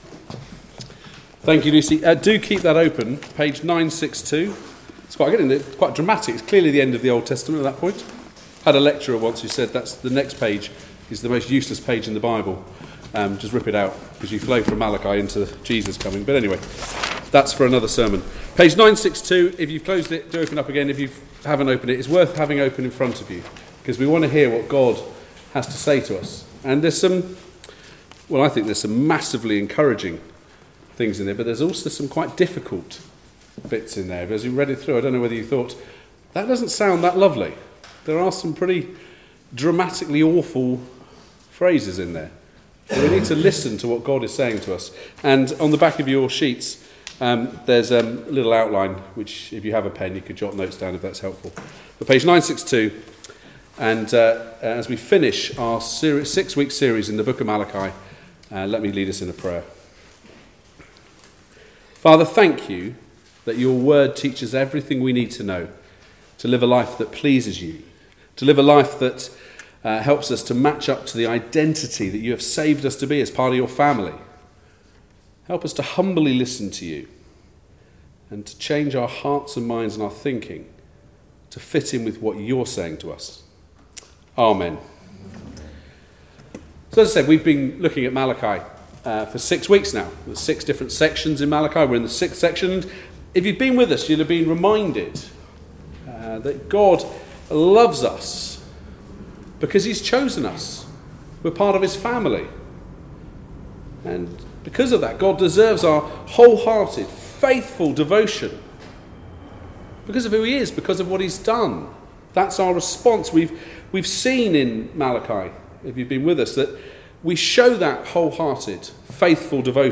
1-6 Service Type: Weekly Service at 4pm Bible Text